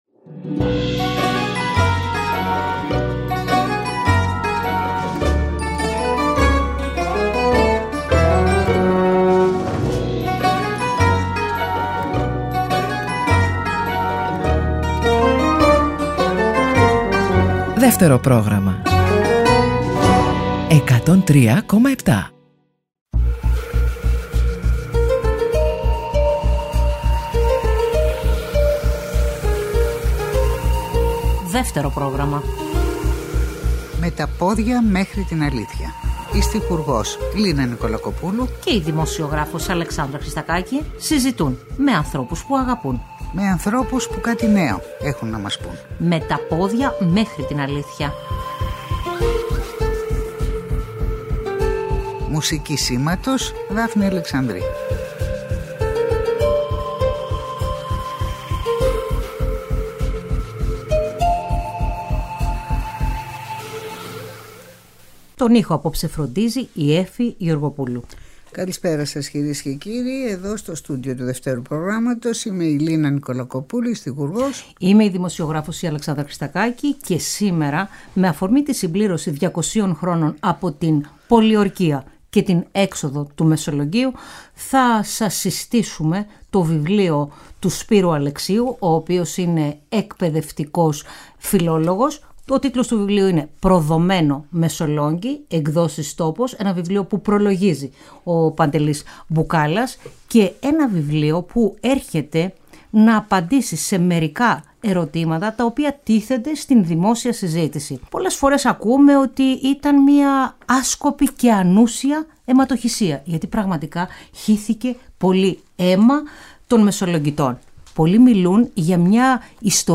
ήταν σημερινός καλεσμένος στο Δεύτερο Πρόγραμμα